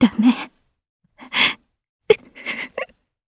Ripine-Vox_Sad_jp.wav